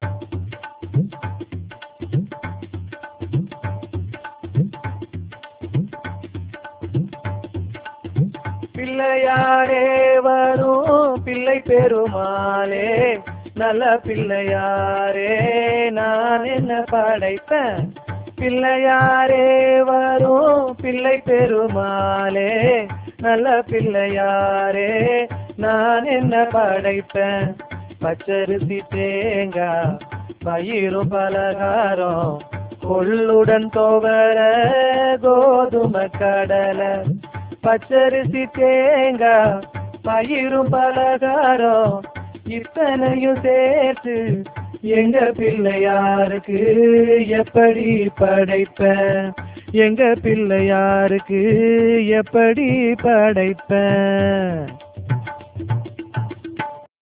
3.4.1 வேளாண்மைத் தொழிற்பாடல்கள்
இந்த ஏற்றத்திலிருந்து நீர் இறைக்கும் போது பாடல்கள் பாடுவர்.
சால் பிடித்து நீர் இறைப்பவர் பாடல் பாடுவார்.